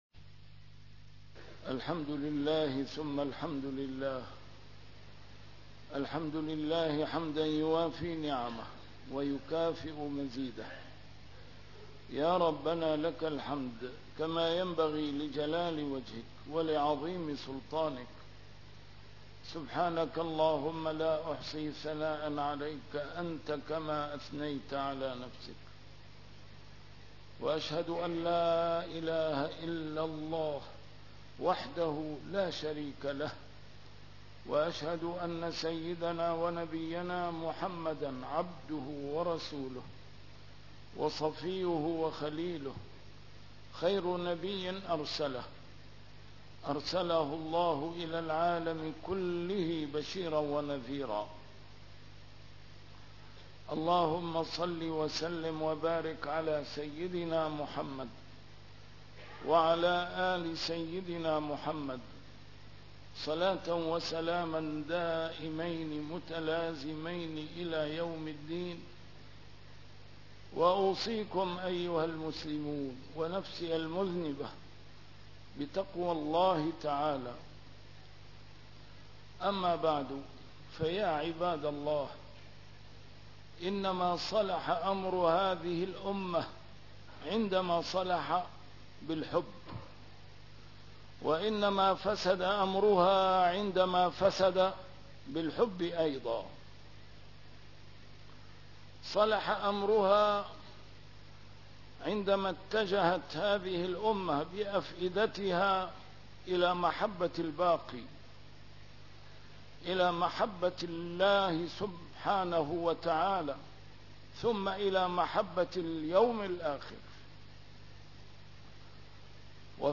A MARTYR SCHOLAR: IMAM MUHAMMAD SAEED RAMADAN AL-BOUTI - الخطب - سر صلاح الأمر حب الله سبحانه وتعالى